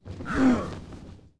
walk_1.wav